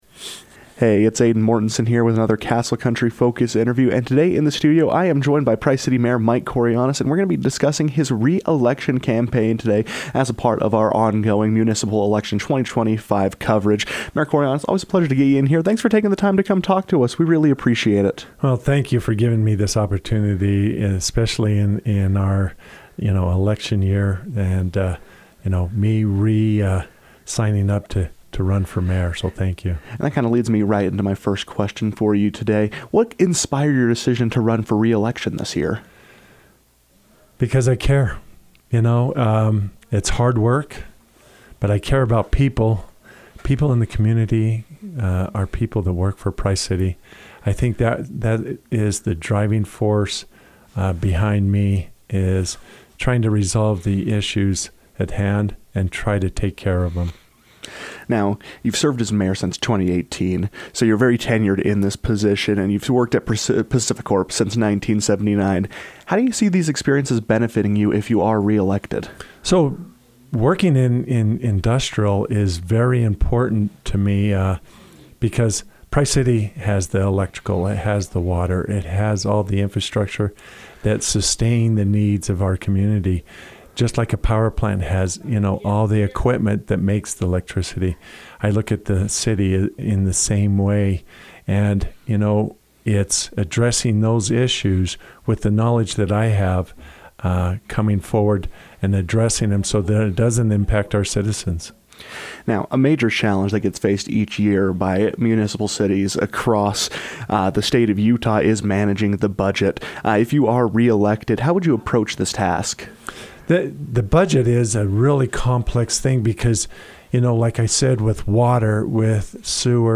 Price City mayoral candidate Michael Kourianos joined the KOAL newsroom to discuss his campaign, platform, and what he aims to accomplish if elected.
All candidates in the 2025 municipal election cycles are entitled to one free interview.